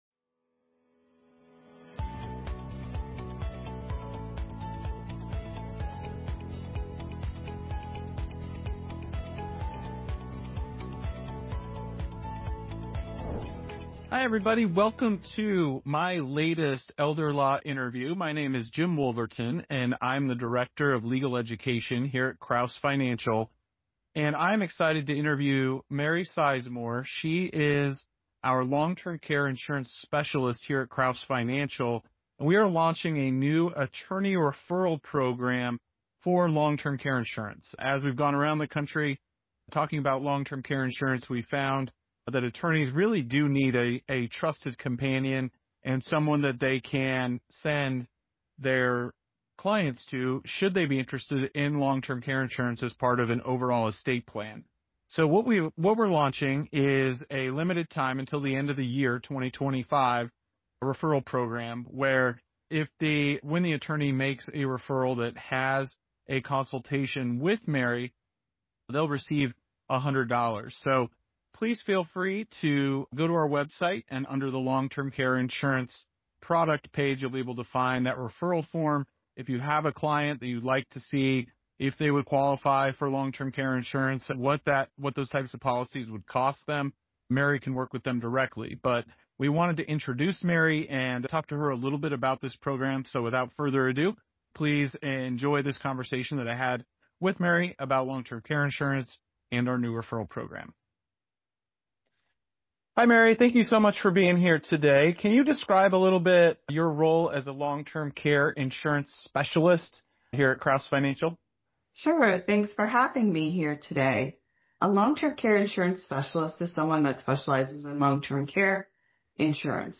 In this Elder Law Interview